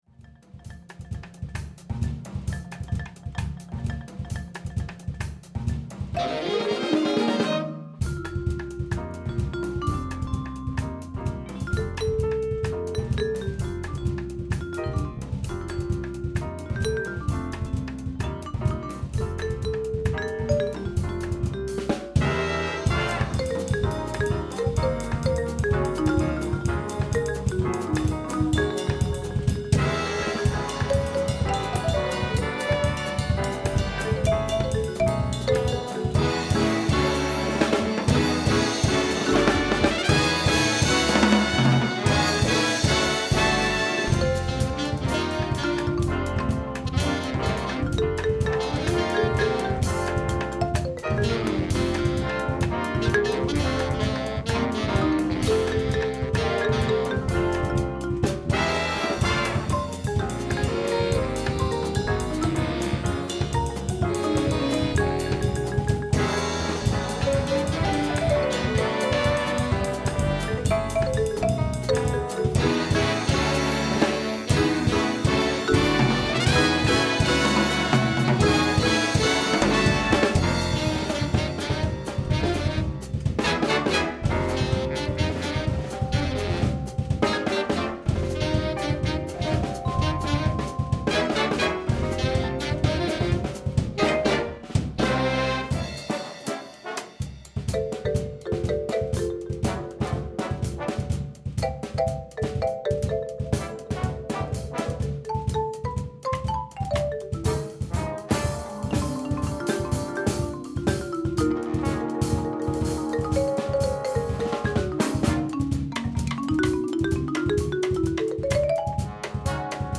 for Jazz Ensemble
Vibes/Marimba Feature
(5/4/4, Vibes/Marimba, P-B-Drs) 17 total parts + Score
Great rhythm section workout in cut time @ qtr=120!